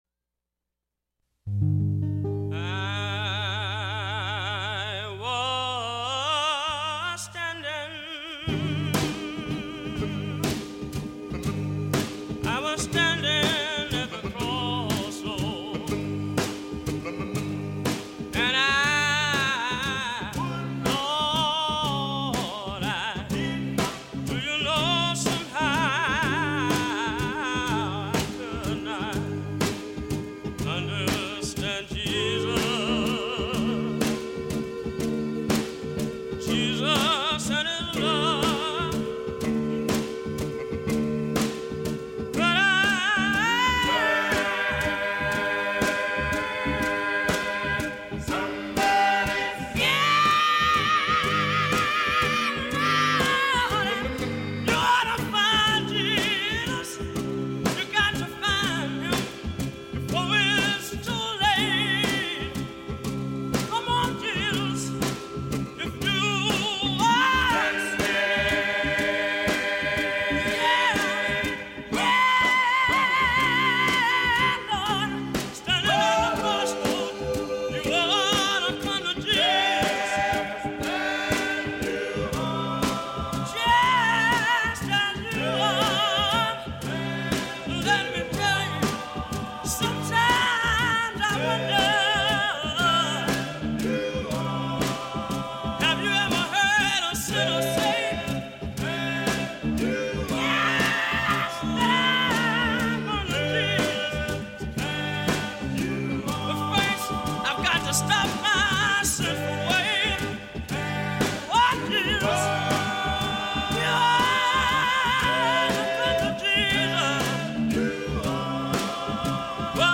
Scratchy vanity 45s, pilfered field recordings, muddy off-the-radio sounds, homemade congregational tapes and vintage commercial gospel throw-downs; a little preachin', a little salvation, a little audio tomfoolery.